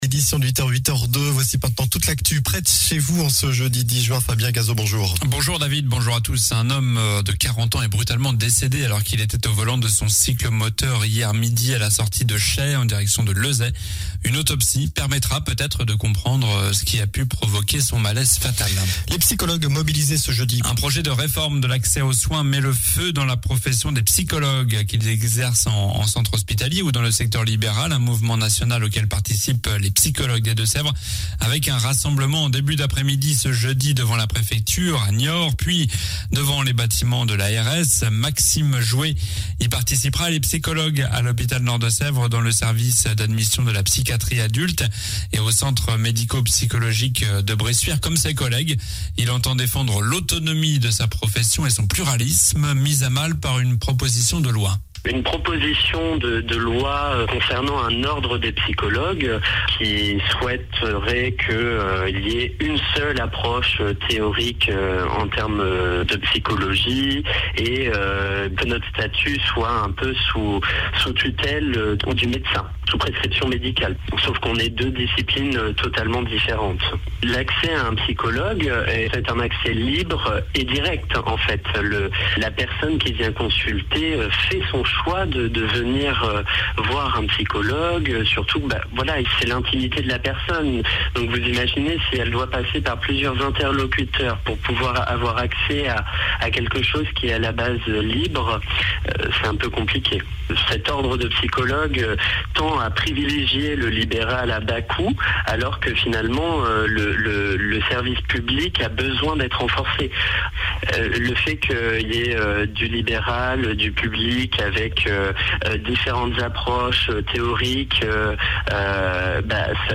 Journal du jeudi 10 juin (matin)